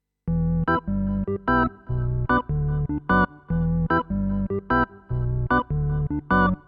welches arpegio, beziehungsweise welcher preset ist das? da habe ich was nettes gefunden auf meinem MoXF6, und hab' es ausgeschaltet ohne mir vorher zu notieren welchen knopf ich da gerade gedrückt hatte. ich find' es nicht wieder. könnte ihr mir sagen welches das im angehängten beispiel ist (immerhin hatte ich es aufgenommen)